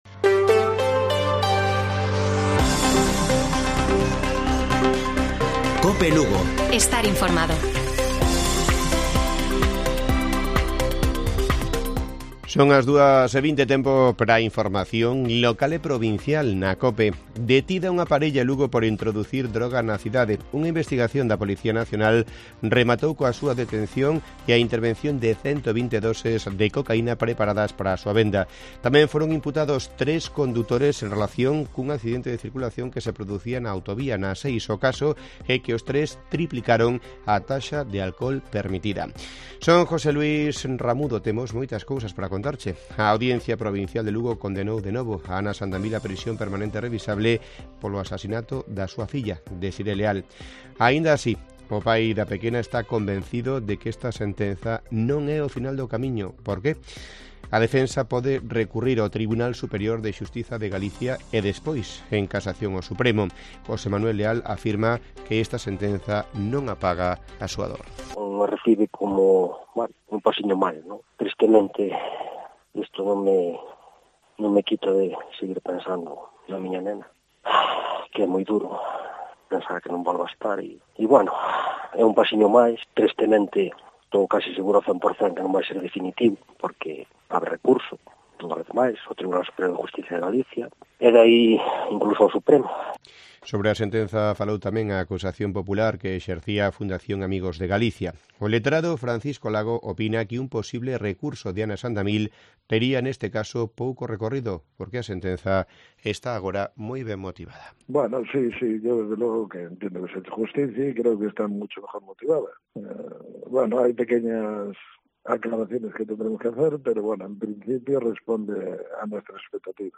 Informativo Mediodía de Cope Lugo. 20 de marzo. 14:20 horas